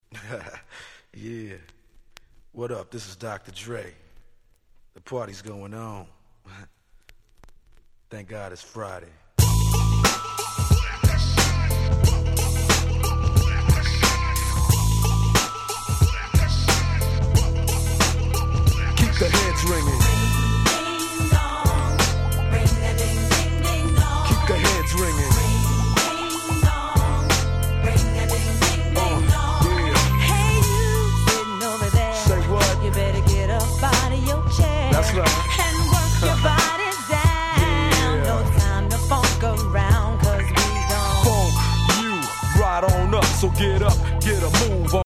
95' West Coast Hip Hop Classic.